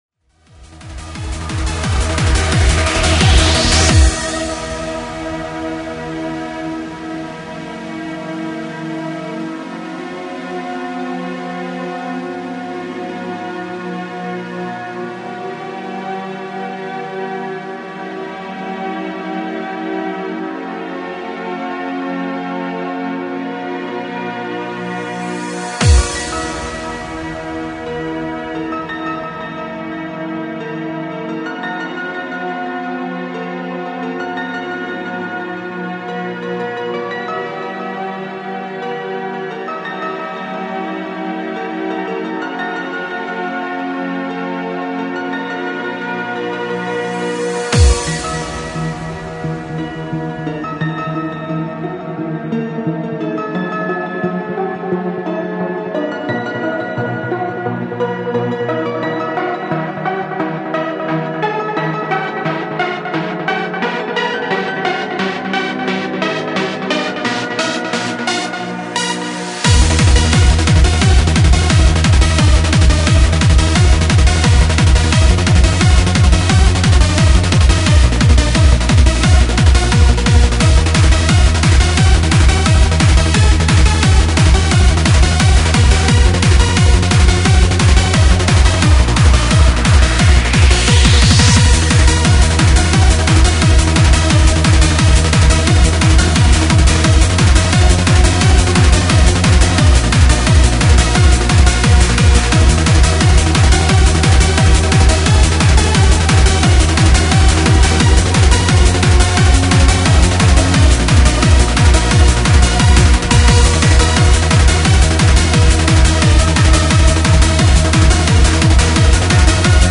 driving, classical, gothic trance atmospherics